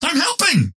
dynamo_use_power3_08.mp3